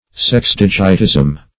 Search Result for " sexdigitism" : The Collaborative International Dictionary of English v.0.48: Sexdigitism \Sex*dig"it*ism\, n. [Sex- + digit.]